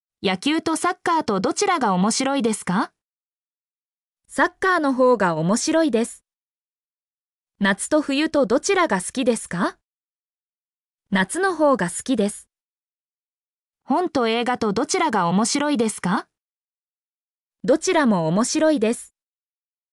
mp3-output-ttsfreedotcom-84_FHb1ykeH.mp3